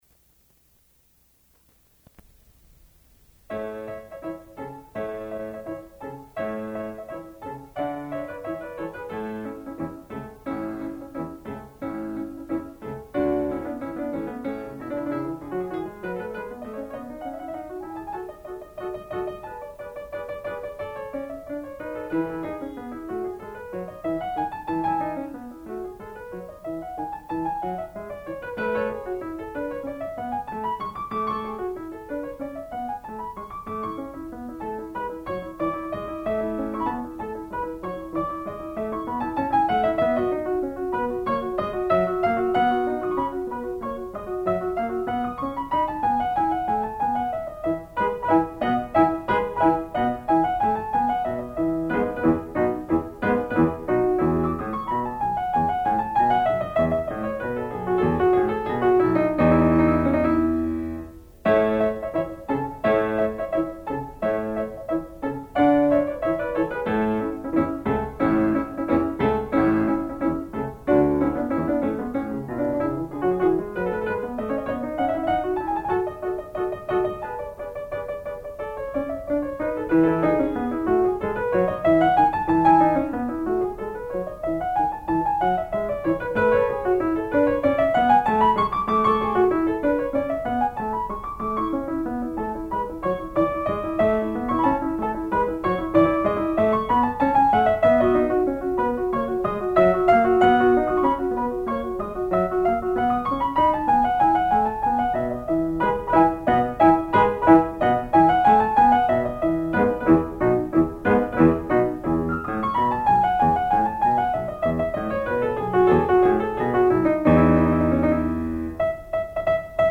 Piano Recital